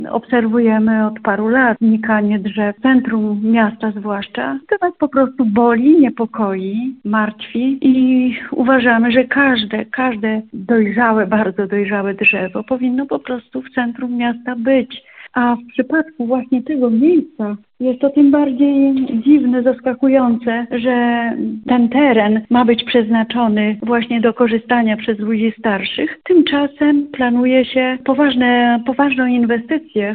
mówiła jedna z ełczanek